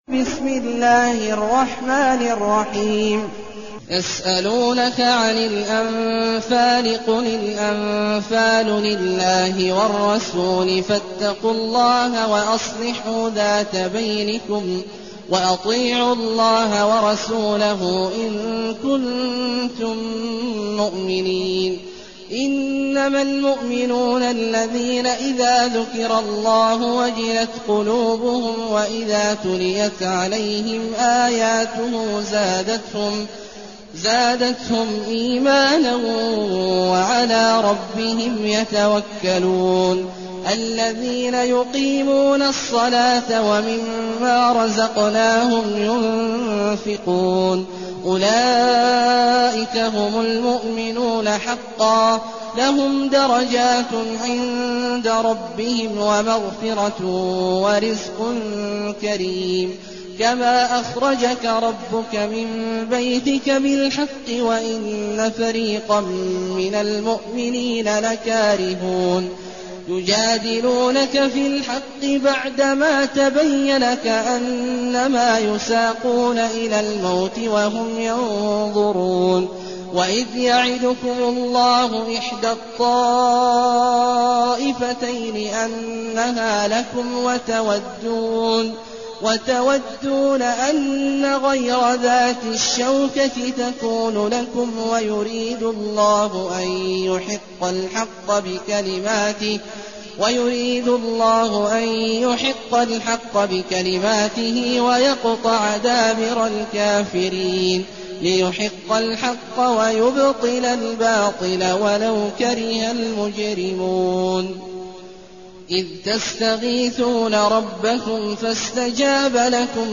المكان: المسجد النبوي الشيخ: فضيلة الشيخ عبدالله الجهني فضيلة الشيخ عبدالله الجهني الأنفال